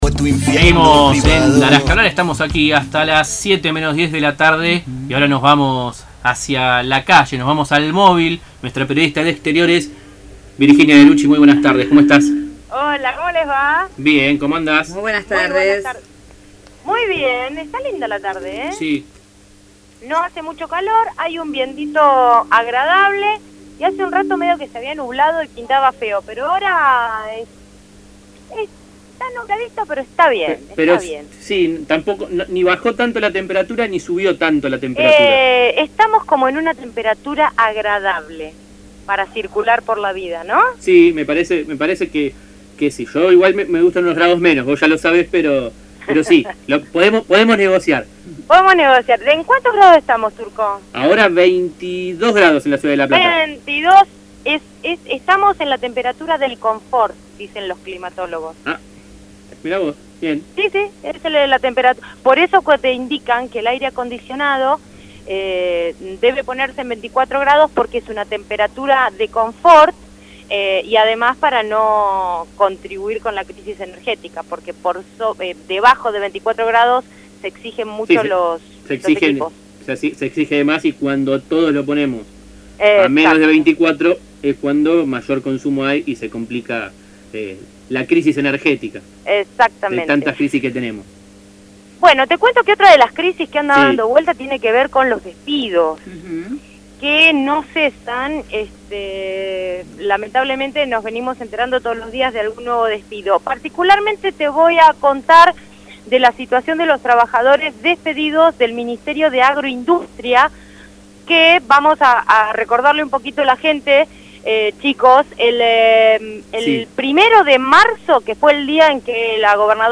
MÓVIL/ Situación de trabajadores despedidos del Ministerio de Agroindustria